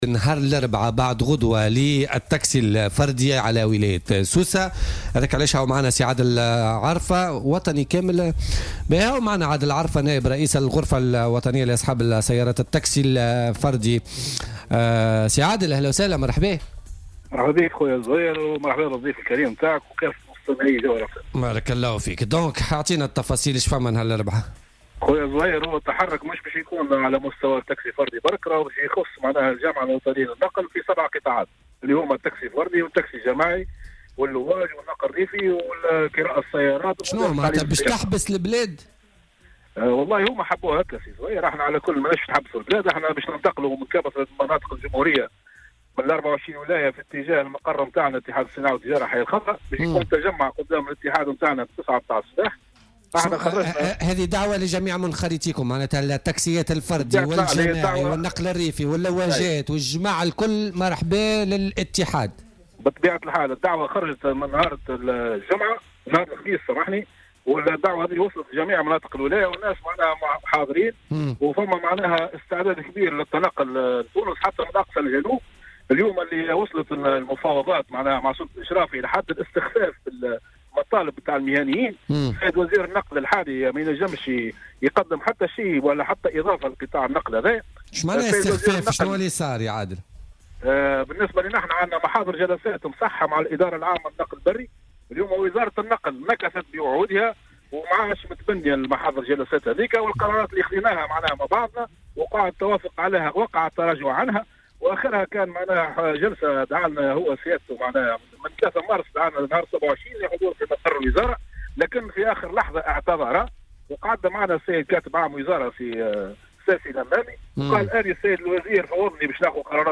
وأوضح في اتصال هاتفي ببرنامج "بوليتيكا" على "الجوهرة اف أم" أن هذا التحرك سيشمل كل القطاعات التابعة للجامعة الوطنية للنقل والمتمثلة في قطاعات التاكسي الفردي والتاكسي السياحي والجماعي واللواج والنقل الريفي وكراء السيارات ومدارس تعليم السياقة.